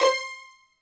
STR HIT C5 2.wav